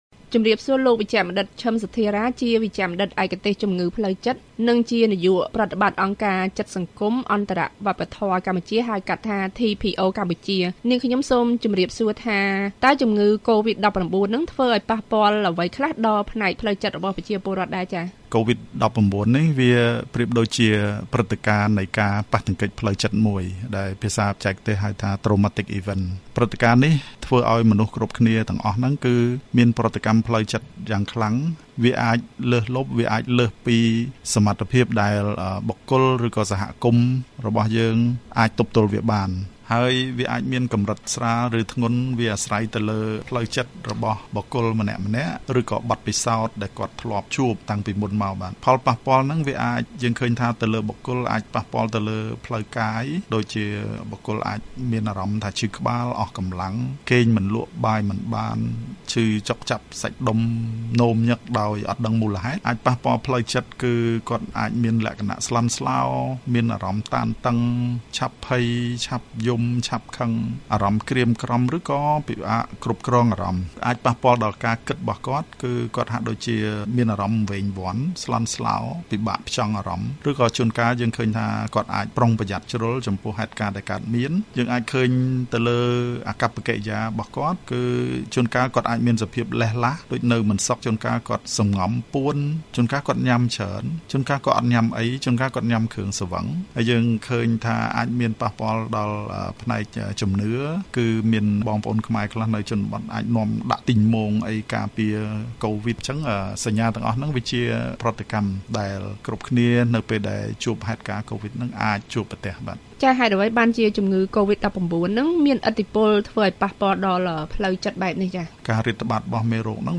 បទសម្ភាសន៍VOA៖ អ្នកឯកទេសជំងឺផ្លូវចិត្តអះអាងថា ប្រជាពលរដ្ឋកម្ពុជាភាគច្រើនកំពុងមានបញ្ហាផ្លូវចិត្តក្រោមឥទ្ធិពលជំងឺកូវីដ១៩